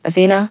synthetic-wakewords
ovos-tts-plugin-deepponies_Billie Eilish_en.wav